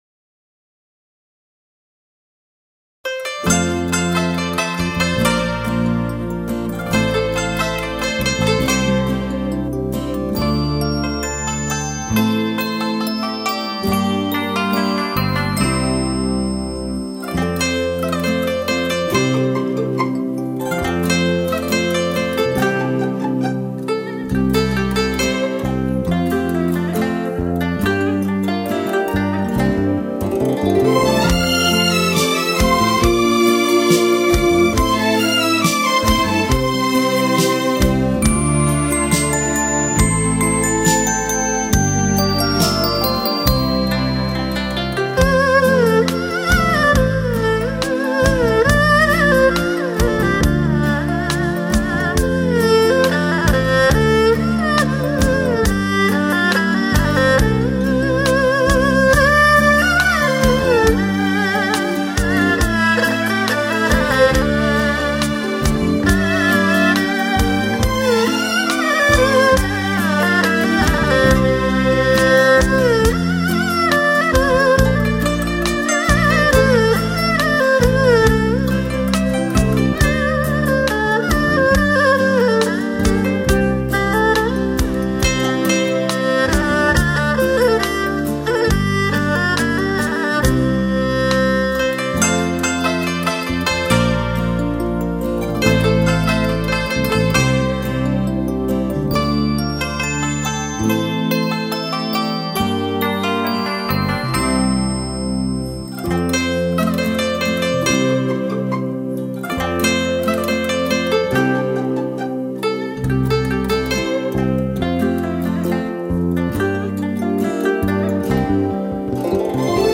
一份闲情，一杯咖啡，一段乐曲足已把人引到一个世外桃源，尽享民族风情，如乘风驾云赏月，沁人心脾……